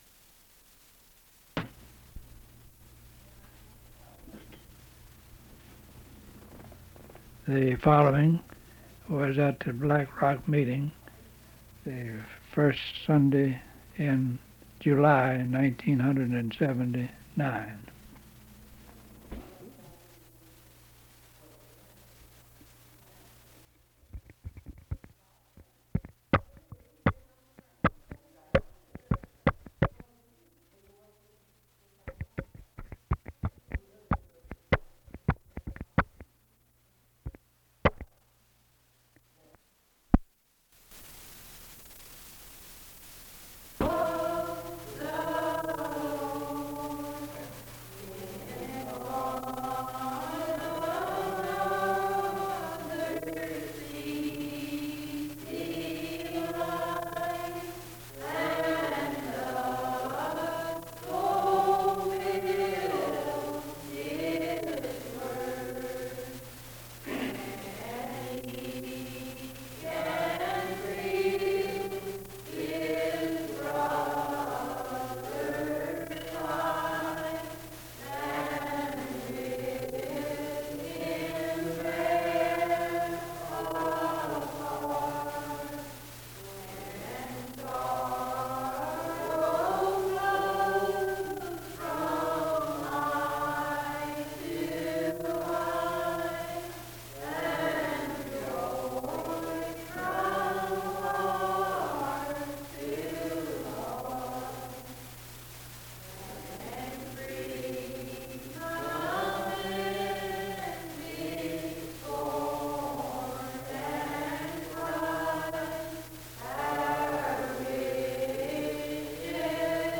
A sermon on general Primitive Baptist themes such as predestination, election, difference from other Christian groups and the broader population, and the sovereignty of God